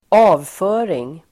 Uttal: [²'a:vfö:ring]